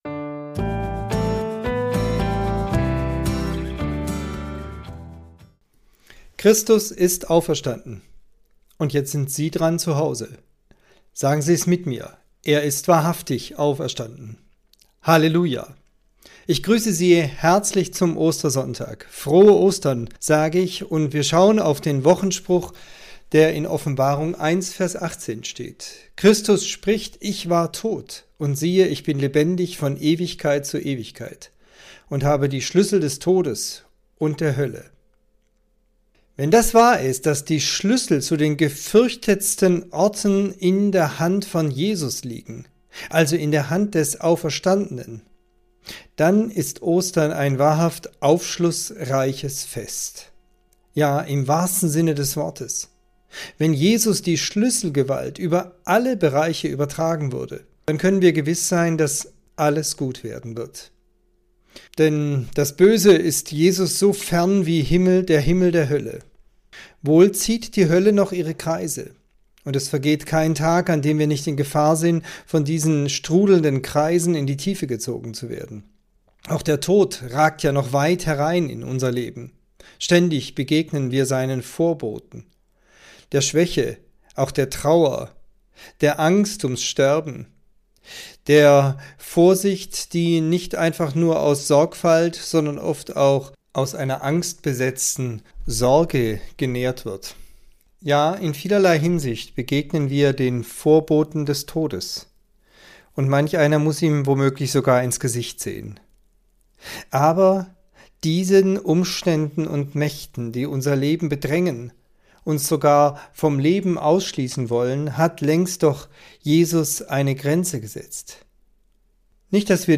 Andacht zum Wochenspruch an Ostern